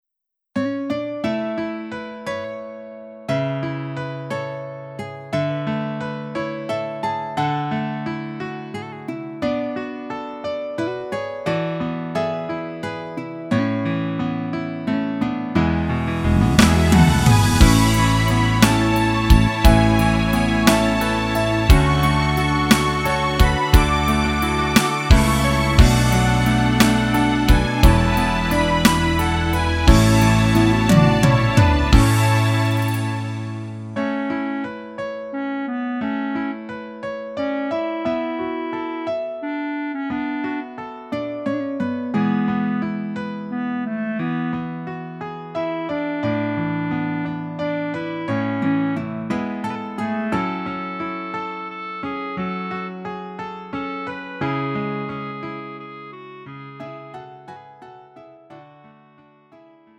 음정 -1키 4:48
장르 가요 구분 Lite MR
Lite MR은 저렴한 가격에 간단한 연습이나 취미용으로 활용할 수 있는 가벼운 반주입니다.